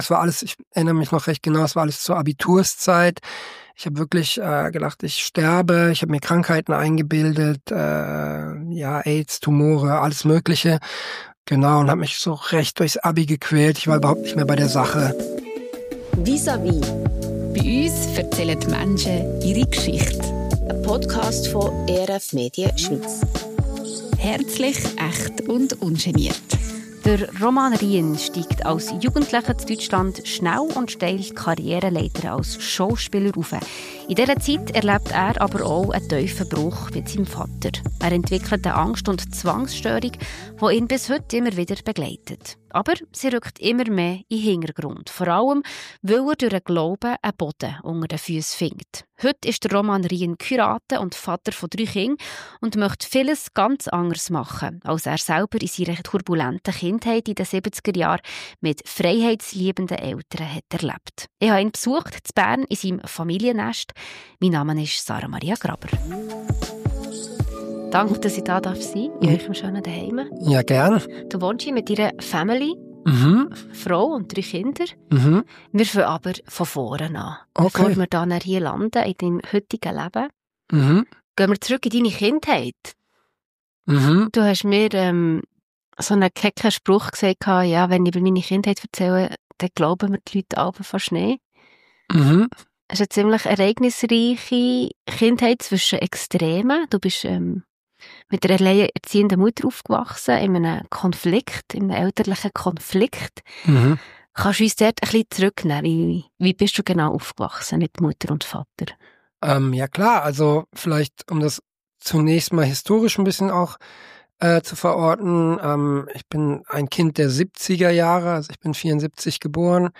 An seinem Küchentisch gewährt er uns einen ehrlichen und inspirierenden Einblick in die wichtigsten Lektionen seines Lebens.